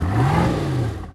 dviratel_gas2.ogg